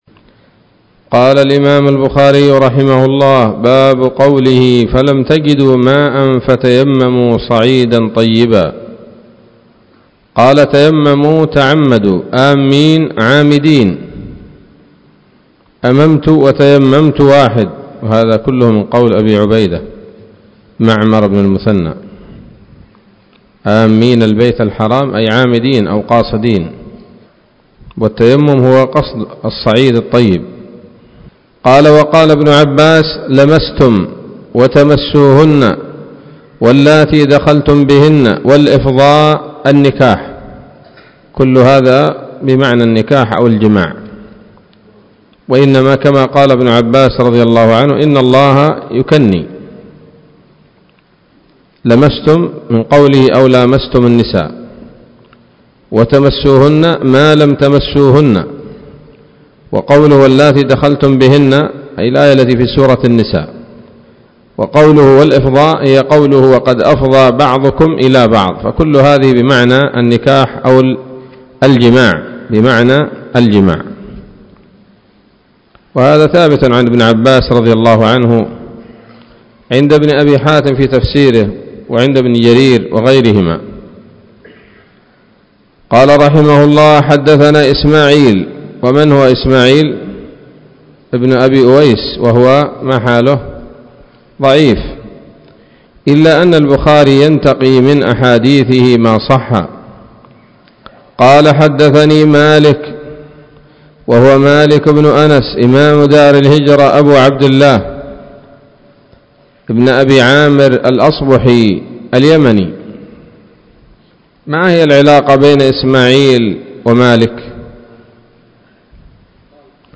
الدرس السابع والثمانون من كتاب التفسير من صحيح الإمام البخاري